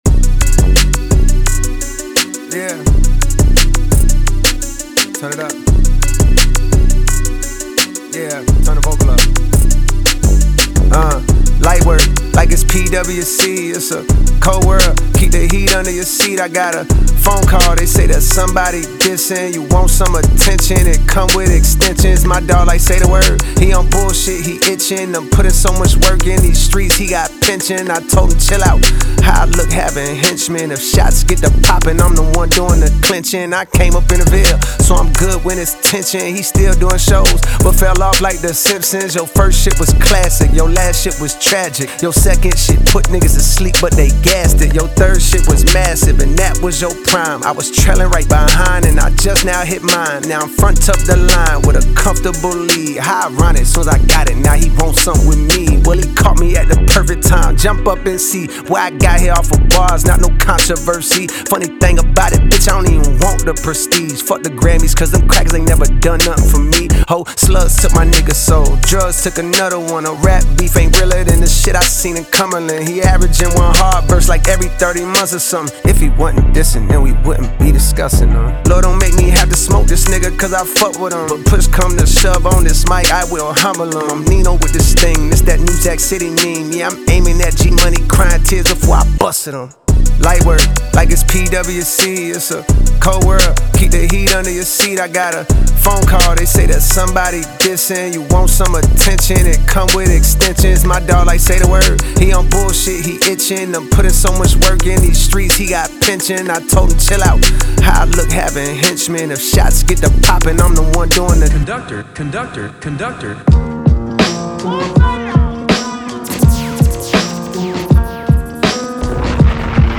music Channel Genre: Hip Hop Lyric ...